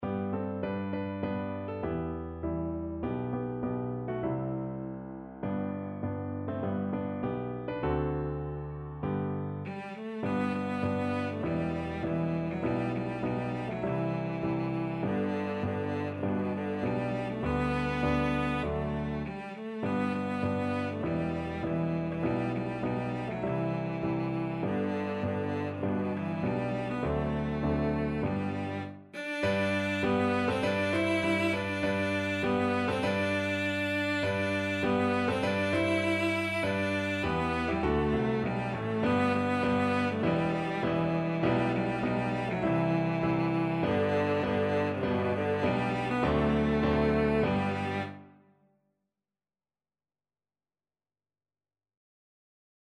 Traditional George Frederick Root Battle Cry of Freedom (Rally Round The Flag) Cello version
G major (Sounding Pitch) (View more G major Music for Cello )
4/4 (View more 4/4 Music)
Moderato
Traditional (View more Traditional Cello Music)